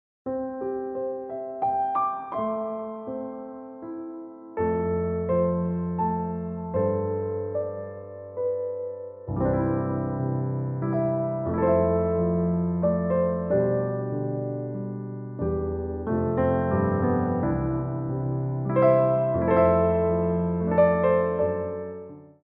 Piano Arrangements
Ronds de Jambé à Terre
3/4 (8x8)